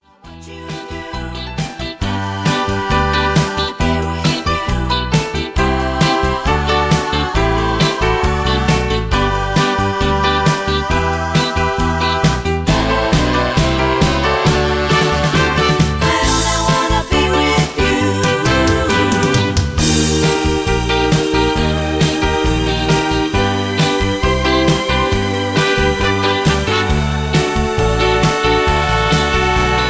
Key-G) Karaoke MP3 Backing Tracks
Just Plain & Simply "GREAT MUSIC" (No Lyrics).